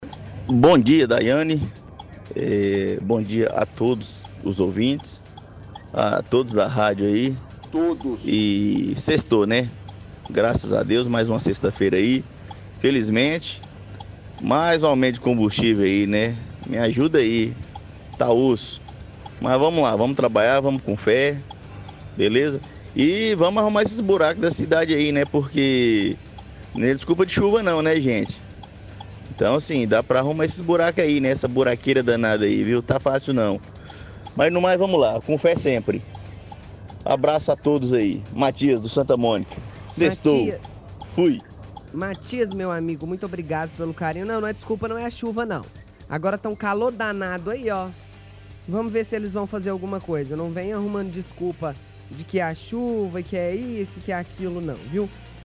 – Ouvinte reclama de buracos na cidade.